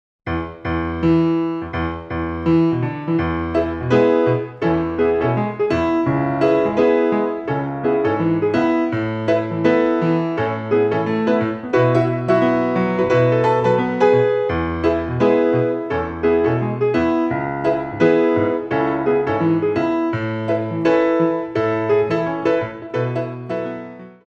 Piano music for the dance studio
Battements jetés